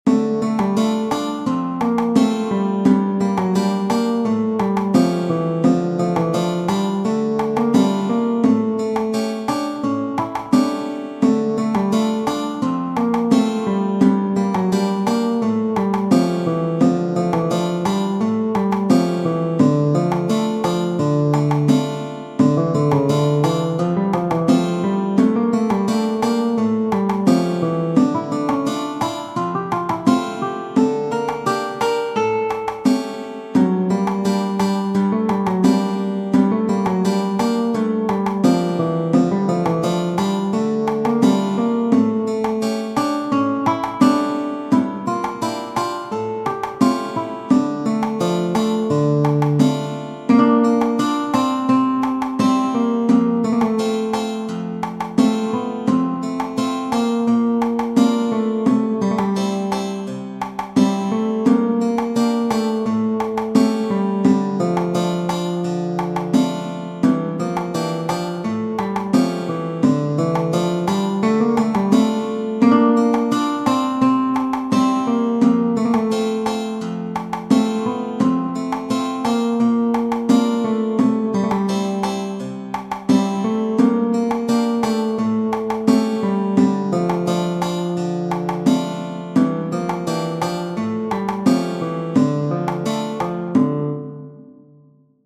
Tradizionale Genere: Folk "Jeni Jol" (in italiano: il nuovo cammino) è un brano musicale e una danza popolare rom della regione di Skopje, in Macedonia.